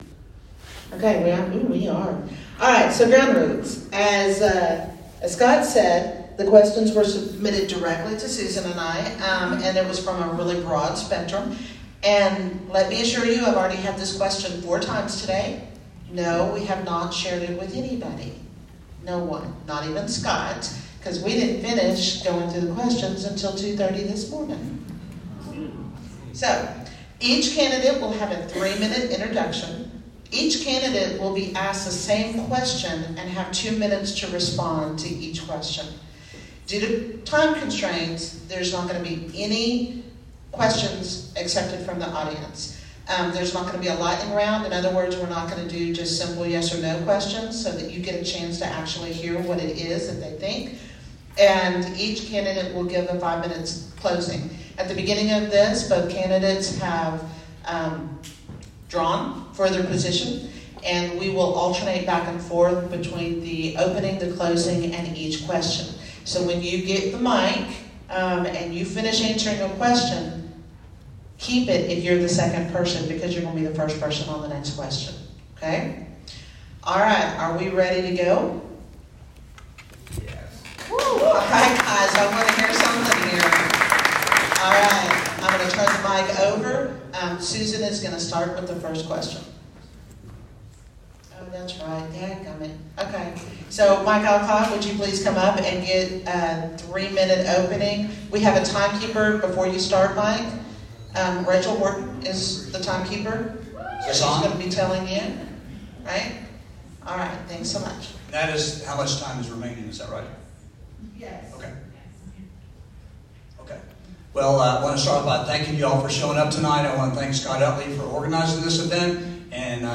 Candidate Forum for Texas House 60